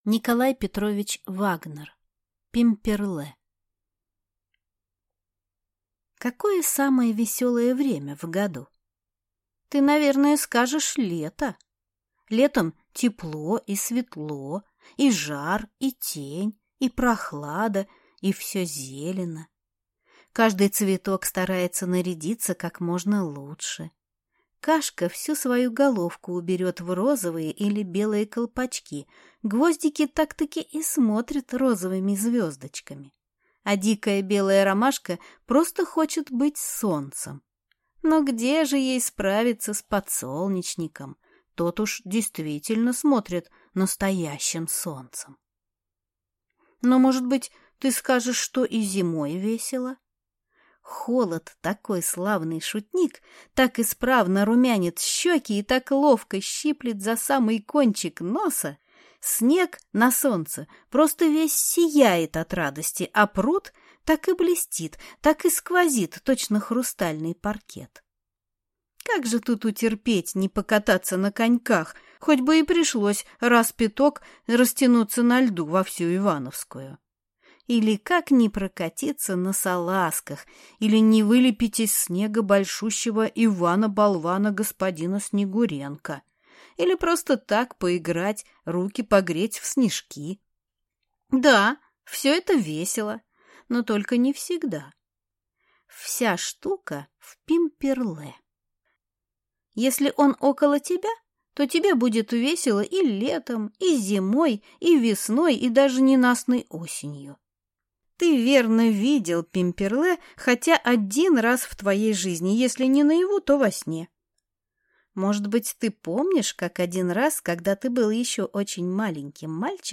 Аудиокнига Пимперлэ | Библиотека аудиокниг